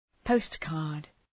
Προφορά
{‘pəʋstkɑ:rd}